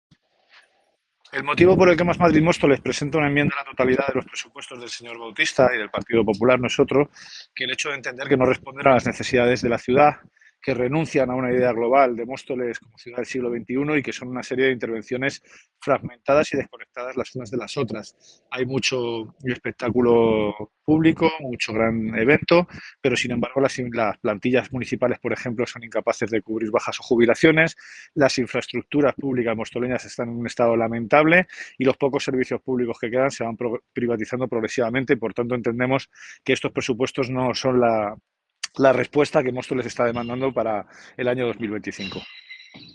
declaraciones-emilio-delgado-presupuestos-2025.mp3